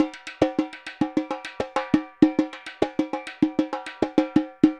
Repique 2_Samba 100_2.wav